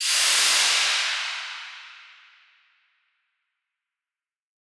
Index of /musicradar/shimmer-and-sparkle-samples/Filtered Noise Hits
SaS_NoiseFilterB-03.wav